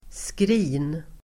Uttal: [skri:n]